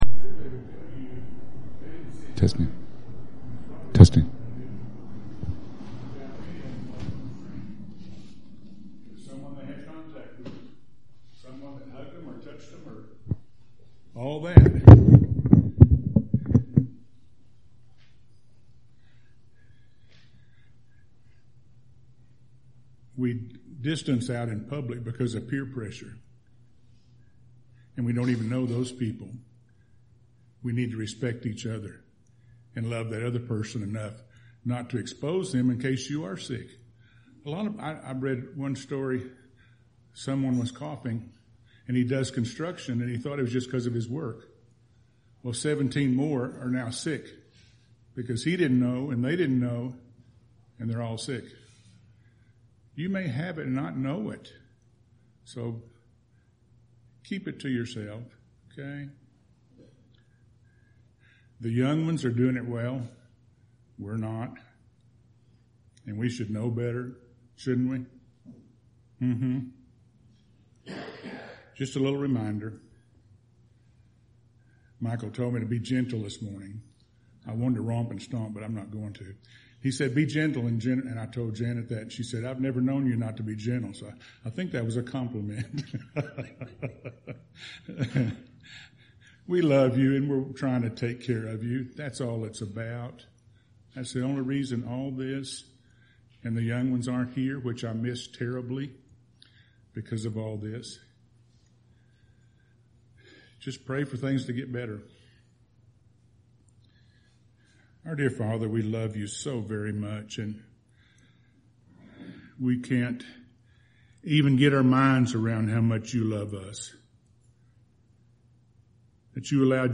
June 28th – Sermons